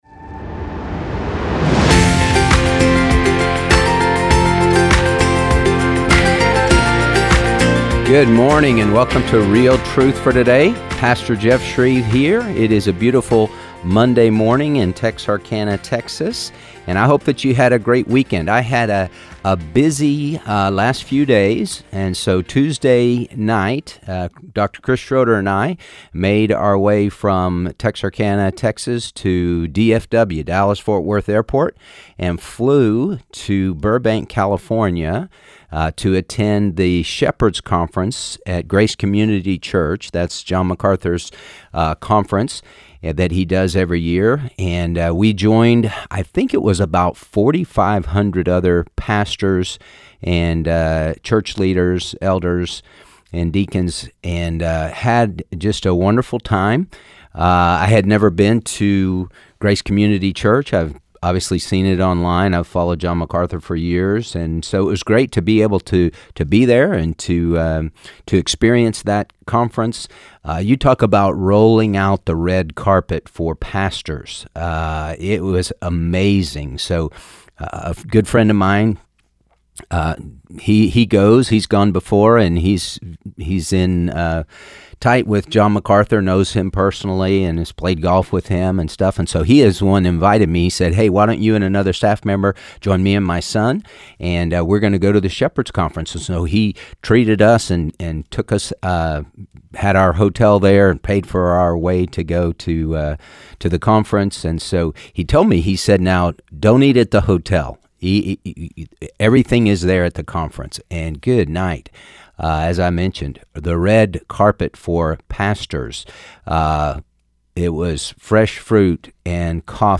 Shepherd’s Conference and Listener Calls